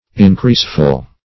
Search Result for " increaseful" : The Collaborative International Dictionary of English v.0.48: Increaseful \In*crease"ful\, a. Full of increase; abundant in produce.